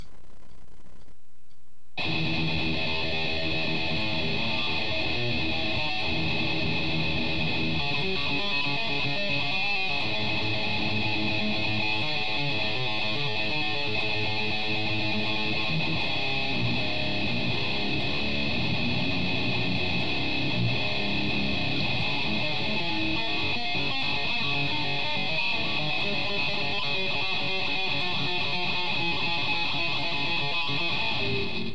Just some noodling on my new 8-Str guitar